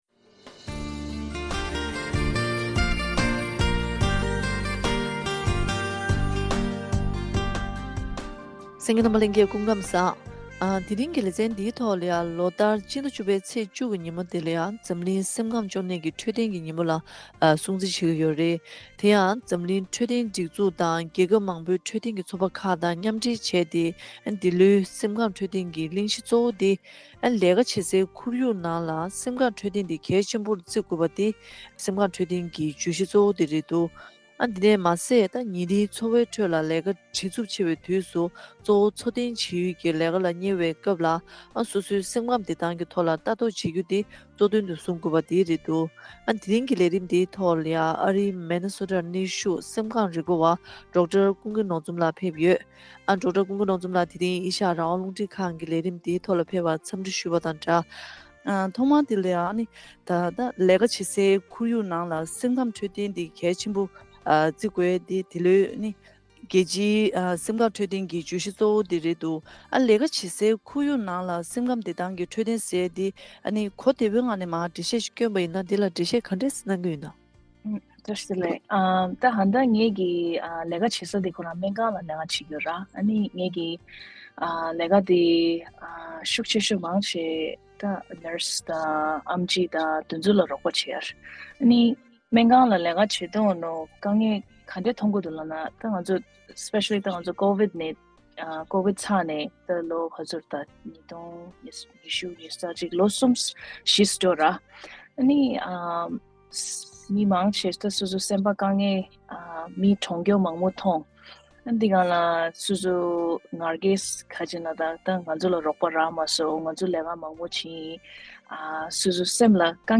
དེ་རིང་གི་དམིགས་བསལ་བཀའ་འདྲིའི་ལེ་ཚན་ནང་།